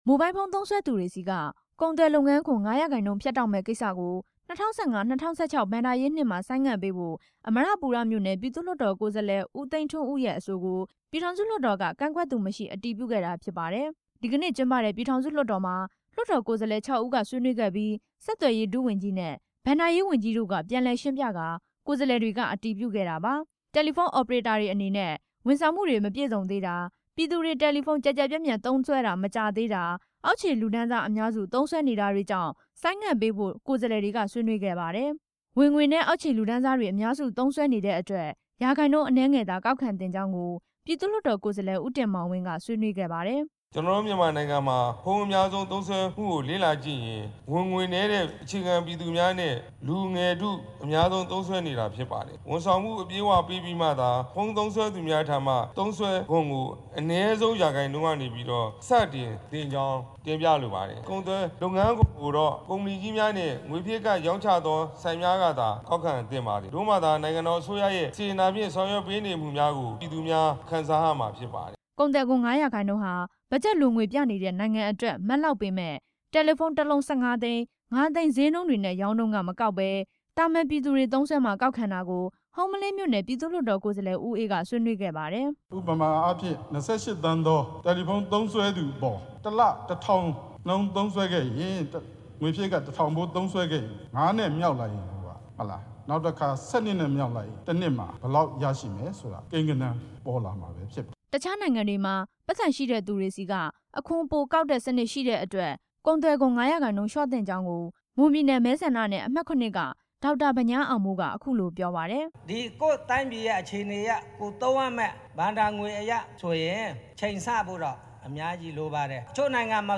မိုဘိုင်းလ်ဖုန်းကုန်သွယ်ခွန် ၅ ရာခိုင်နှုန်းကောက်ခံမှုနဲ့ ပတ်သက်ပြီး ထည့်သွင်း စဉ်းစားသင့်တာတွေကို ဘဏ္ဍာရေးဝန်ကြီး ဦးဝင်းရှိန်က လွှတ်တော်မှာ အခုလို တင်ပြခဲ့ပါတယ်။